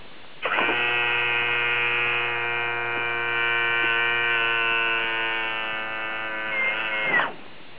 convert samples to 8Khz
gear-up.wav